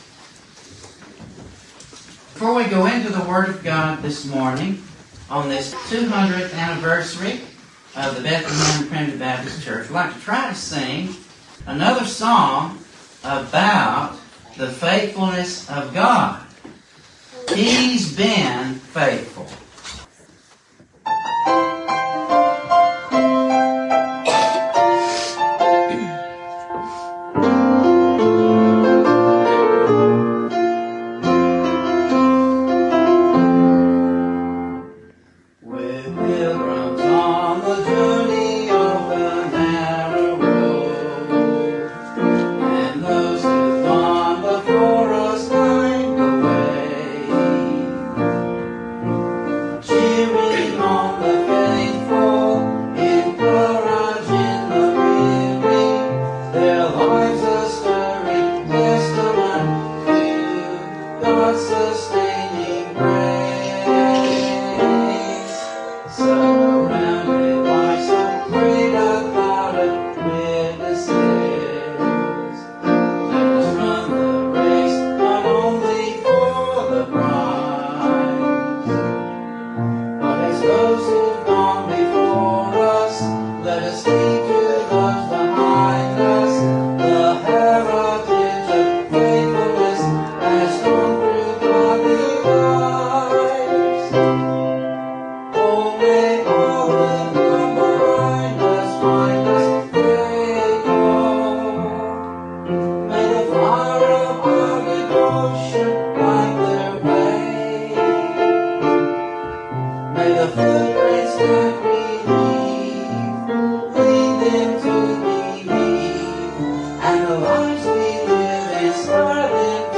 Matthew 9:35-38, Jesus Gathers His Sheep Mar 13 In: Sermon by Speaker